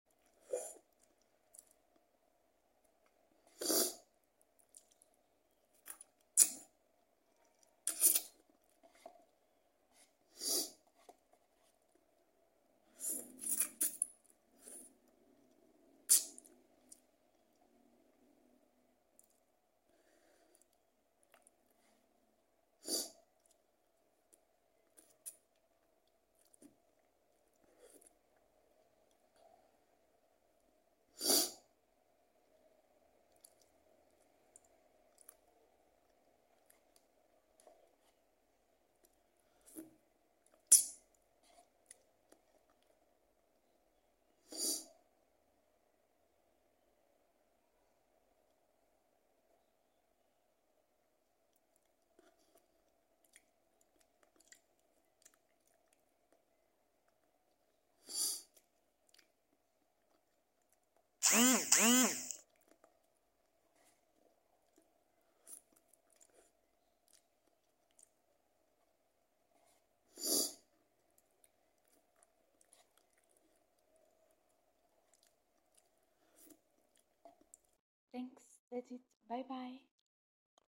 Eating Lamen 👩‍🍳🍜😋 Mukbang sound effects free download
Eating Lamen 👩‍🍳🍜😋 - Mukbang ASMR - Part 4